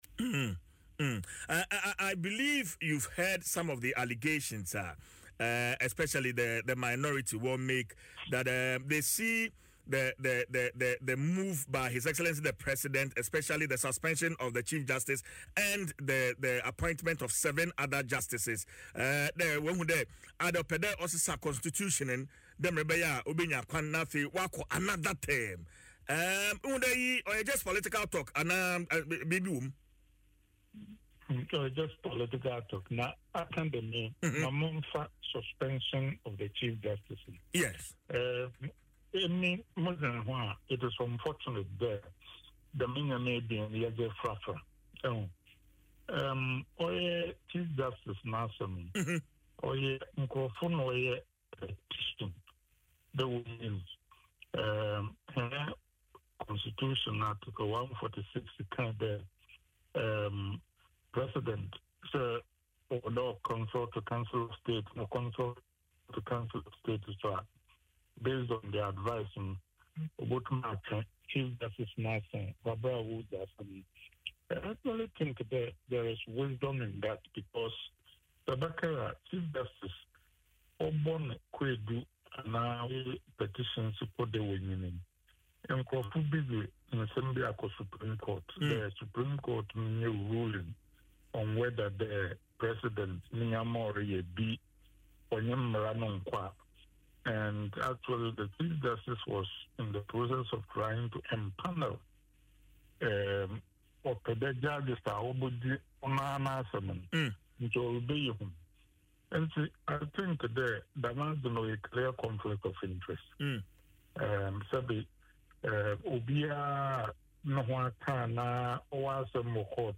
In an interview on Adom FM’s Dwaso Nsem, Dr. Kennedy dismissed the claim as mere political talk.